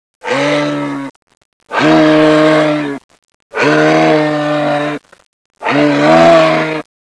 Orignal, Blue Hill, Terra Nova National Park, Terre-Neuve, Canada